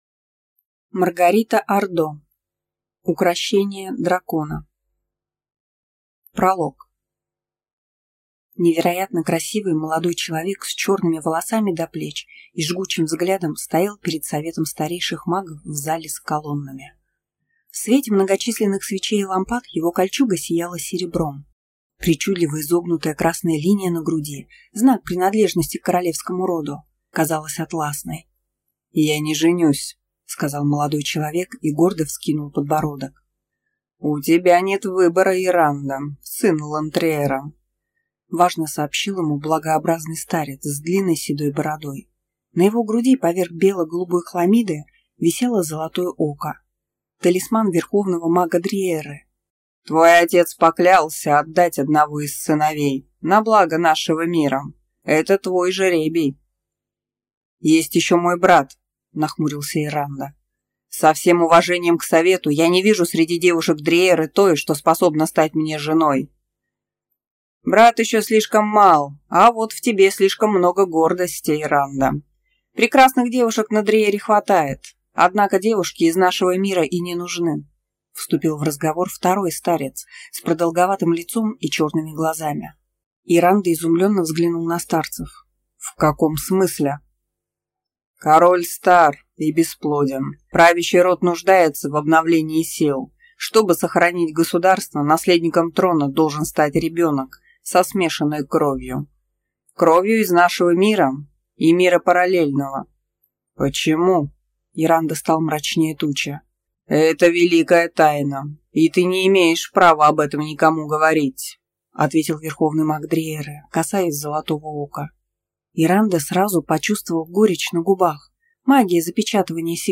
Аудиокнига Укрощение дракона | Библиотека аудиокниг